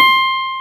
CLAV E5+.wav